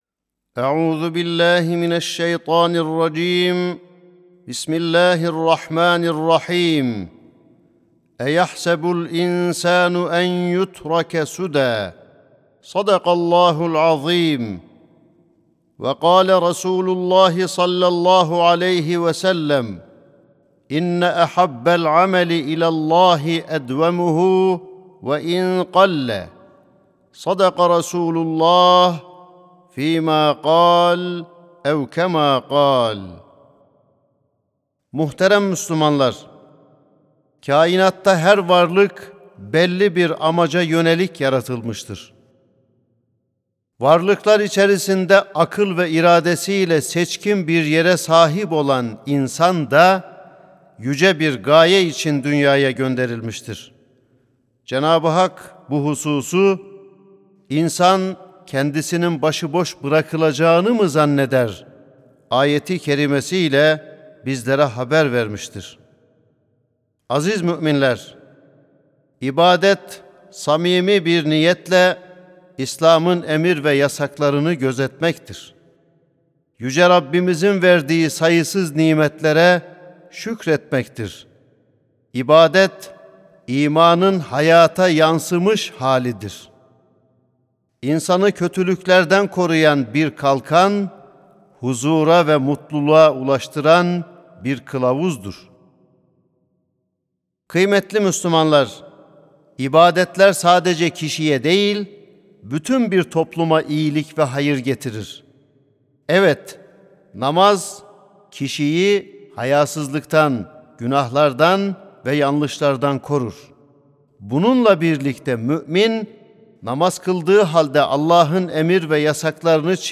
Sesli Hutbe
sesli-hutbe.mp3